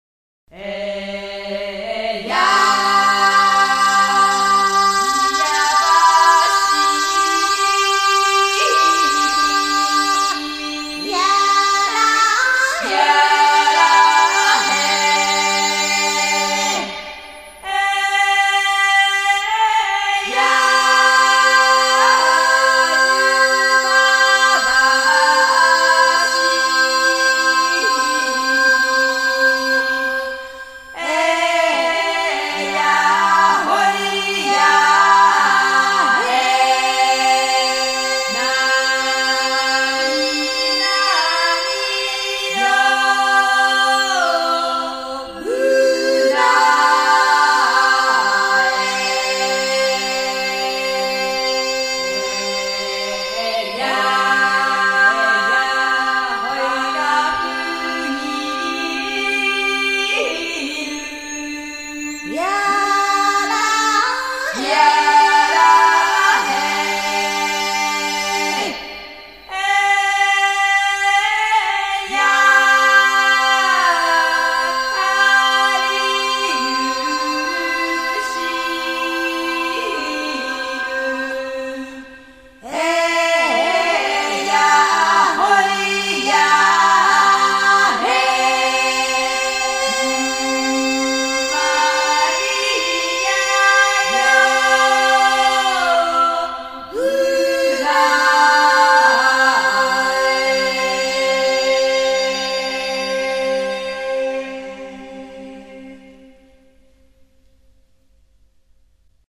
音乐类型：世界音乐
日本 / 冲绳、台湾、太平洋群岛民歌